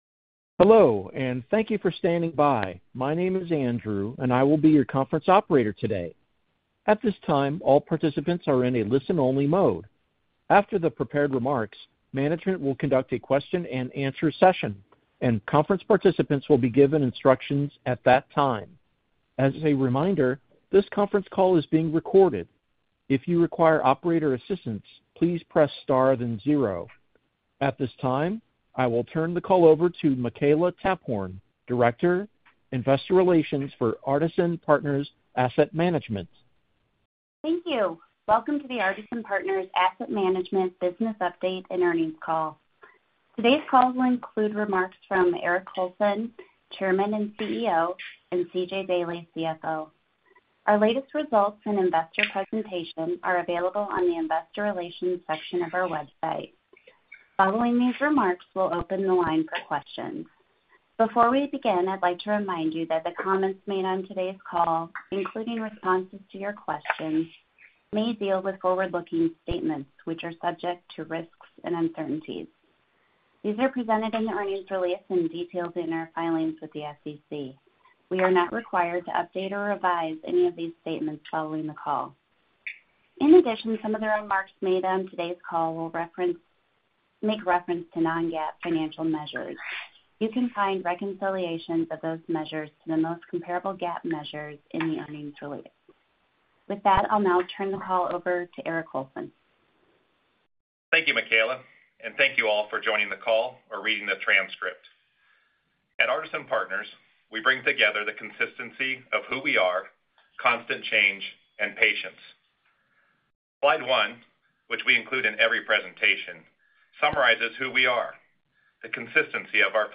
4Q20 Artisan Partners Asset Management Inc Investor Conference Call | Artisan Partners Asset Management Inc